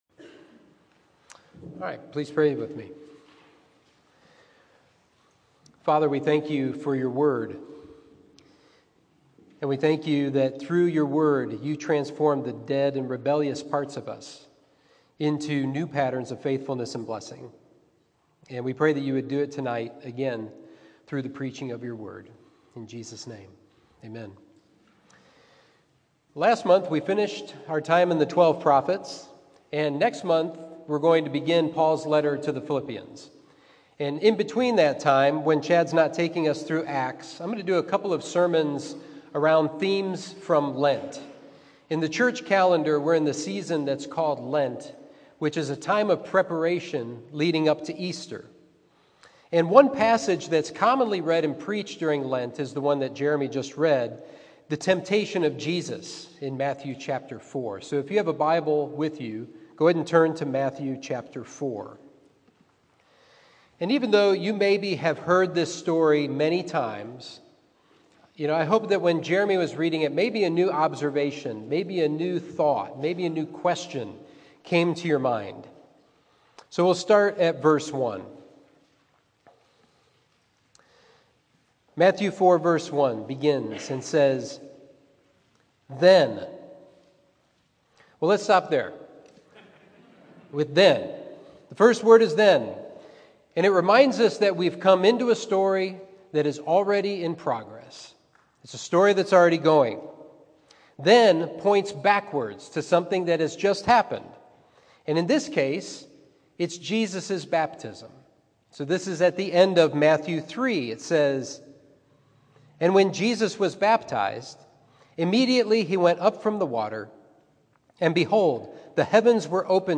Sermon 3/13: For it is Written